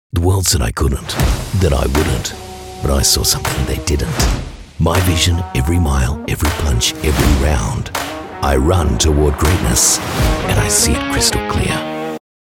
Dynamic, approachable, friendly and natural Australian VoiceOver
Television Spots
Motivating-Believable